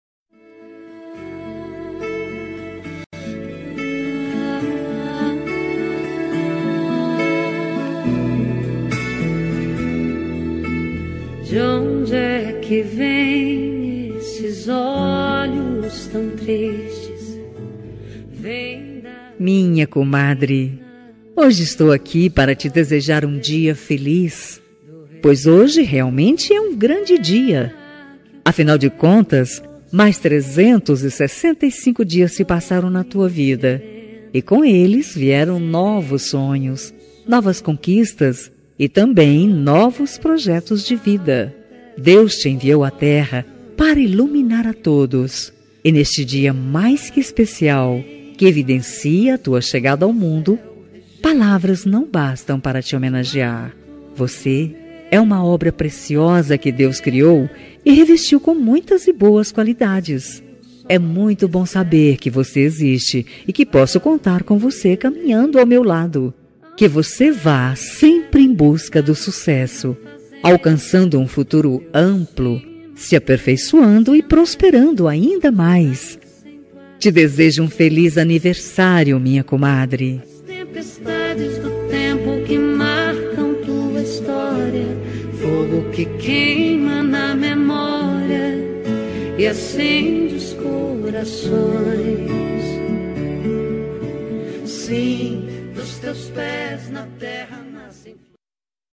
Aniversário de Comadre – Voz Feminina – Cód: 202142